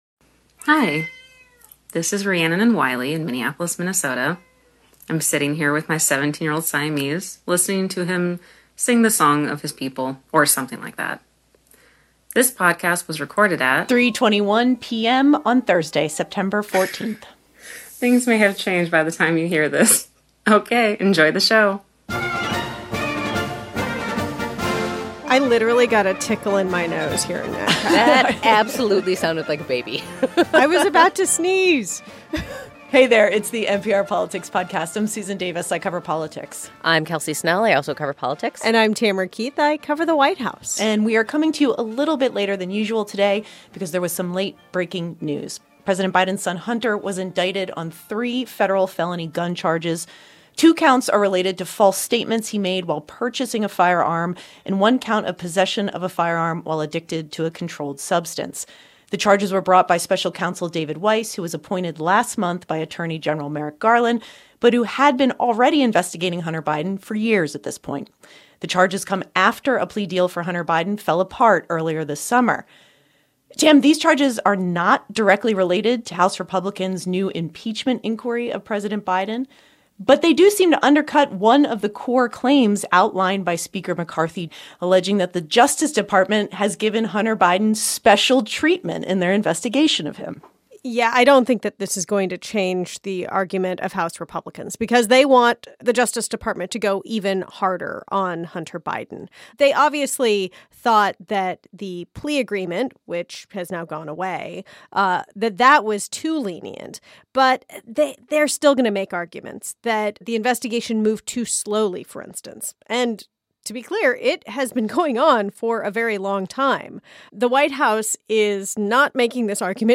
political correspondents